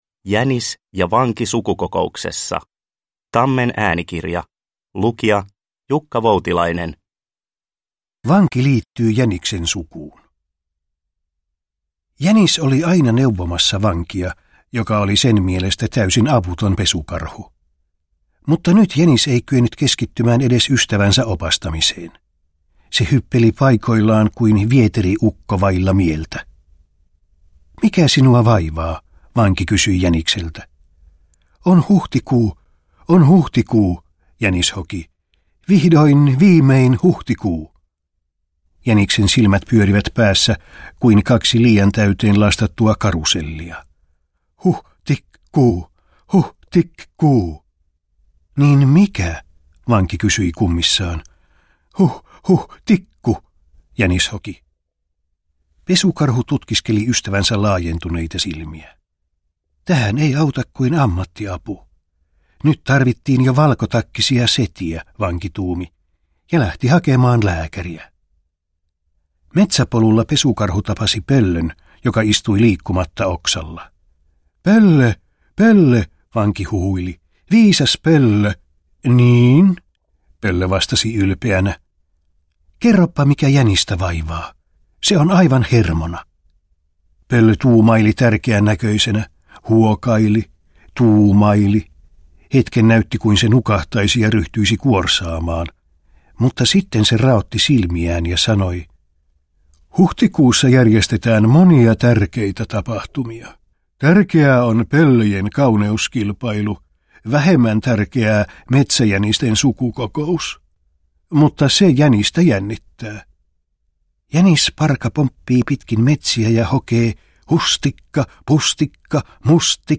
Jänis ja Vanki sukukokouksessa – Ljudbok – Laddas ner